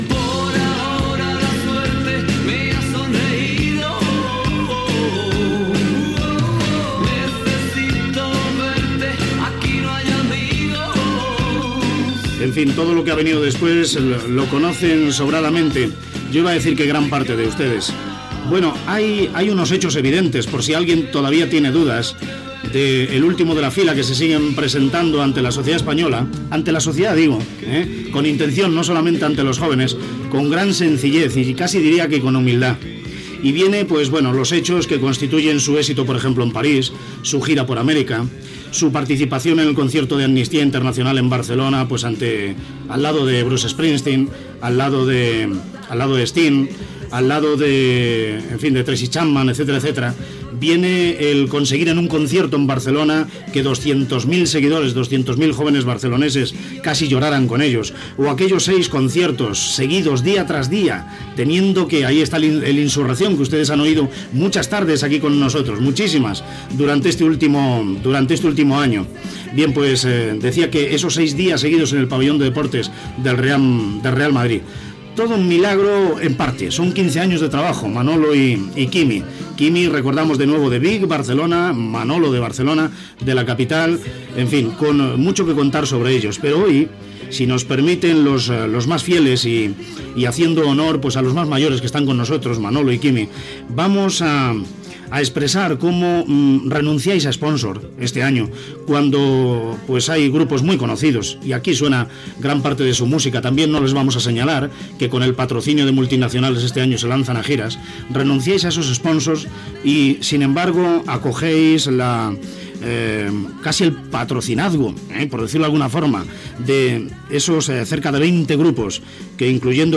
Fragment d'una entrevista a Manolo García i Quimi Portet, components del grup "El último de la fila".
Entreteniment